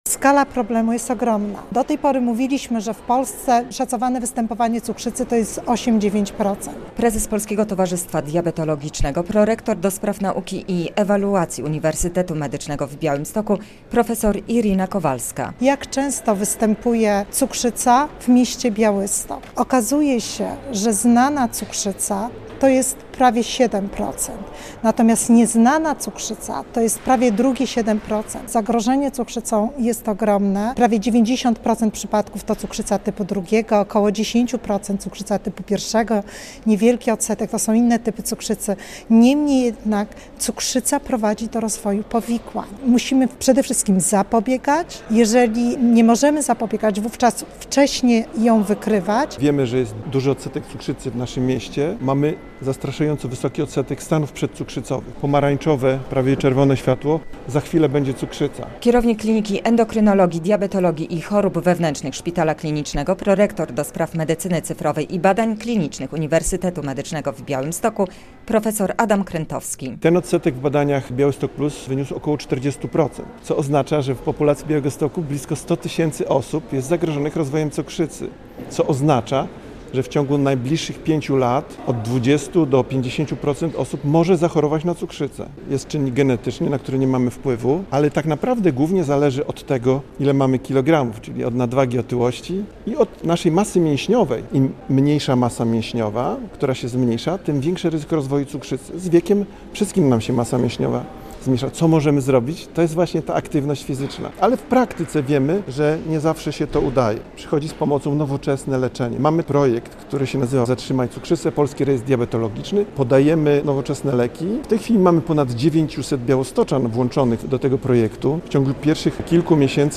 Jak zapobiegać cukrzycy - relacja